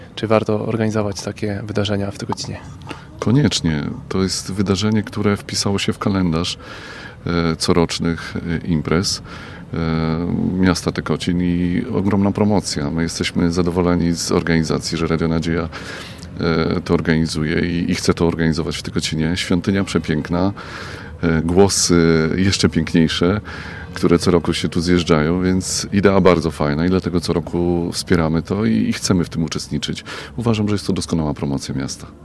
O potrzebie realizowania takich spotkań mówi Mariusz Dudziński, burmistrz Tykocina.